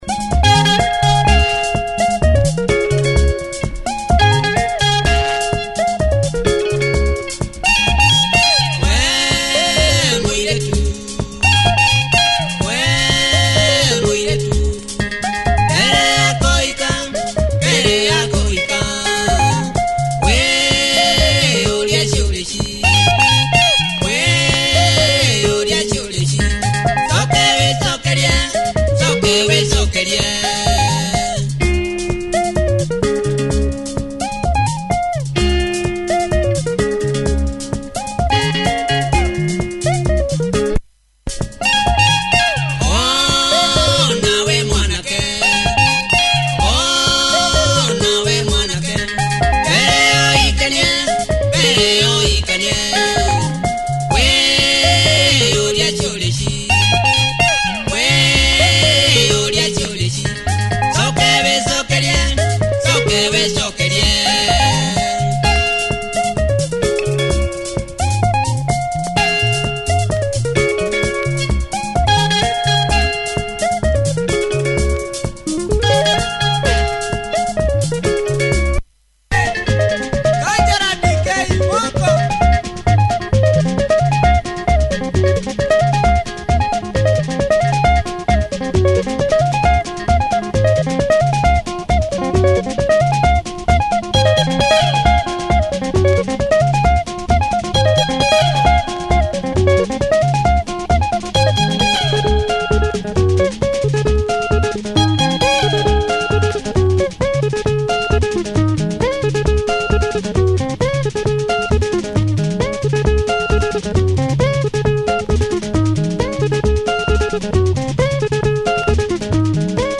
number with a cosmic guitar lick